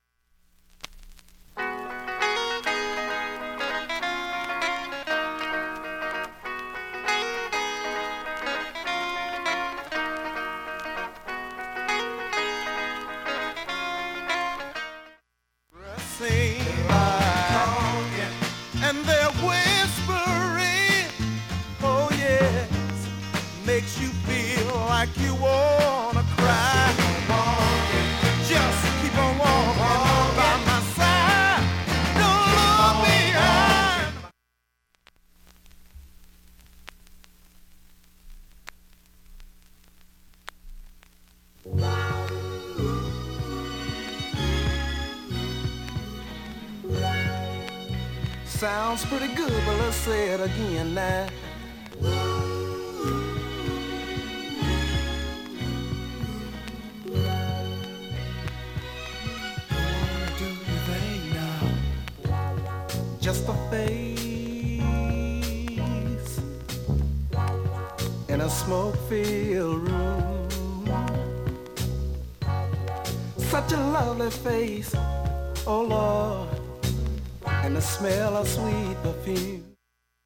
普通にいい音で聴けます
音質良好全曲試聴済み。
A-1始めにかすかなプツが４回出ます